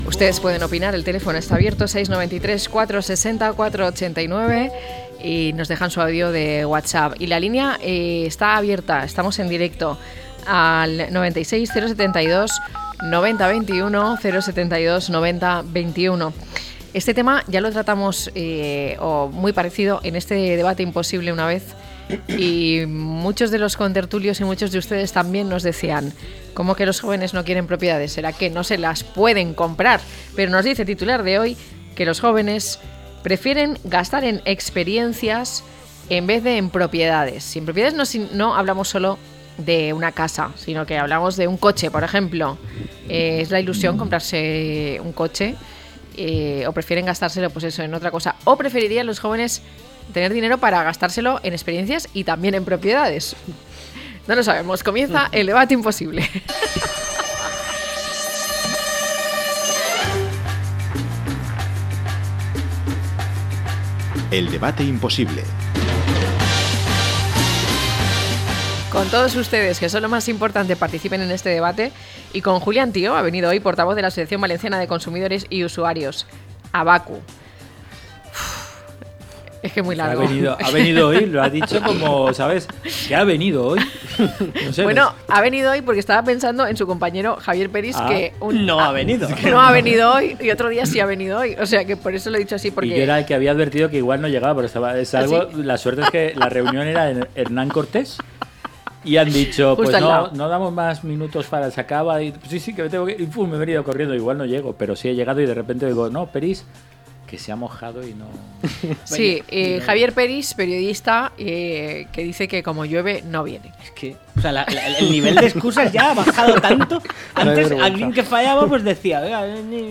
0612-LTCM-DEBATE.mp3